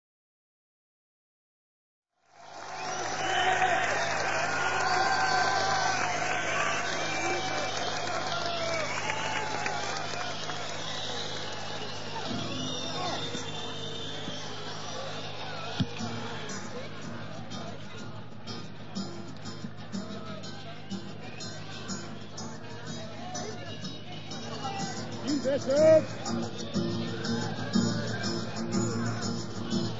Live in Essen - West Germany 1972